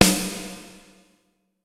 Percusión 1: caja
membranófono
batería
electrónico
golpe
sintetizador